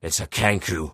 Tags: kenku effects random phrases